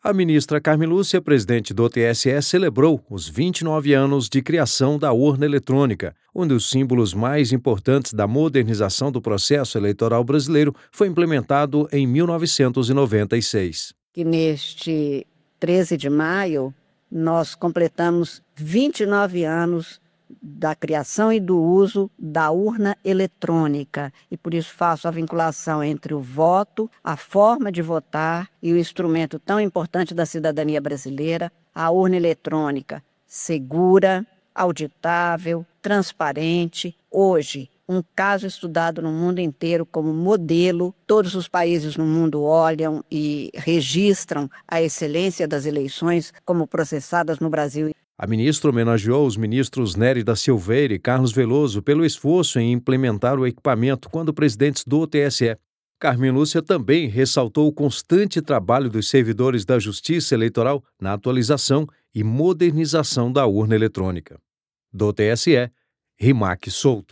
A presidente do TSE fez referência à data na abertura da sessão plenária desta terça (13).